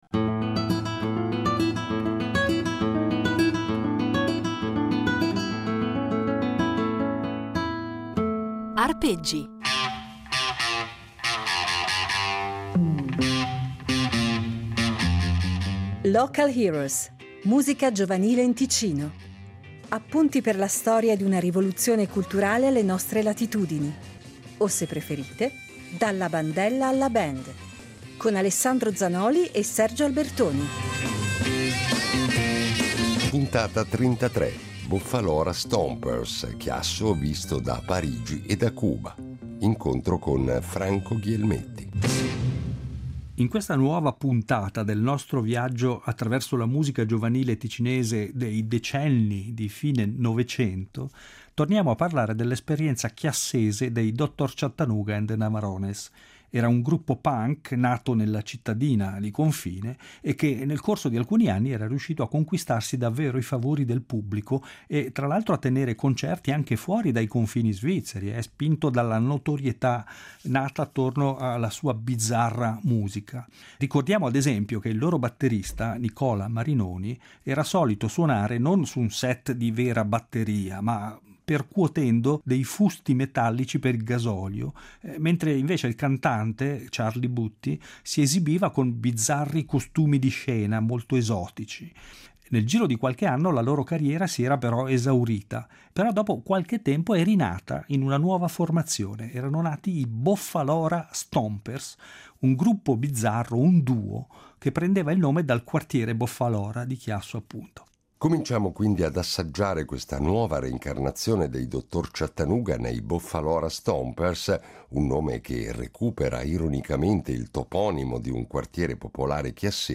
In ognuna delle puntate di “Local Heroes” affronteremo un tema diverso, e daremo la parola a un ospite diverso, ma ascolteremo anche molta musica. Musica piena di energia ed entusiasmo, che vale sicuramente la pena di far risuonare, a distanza di decenni.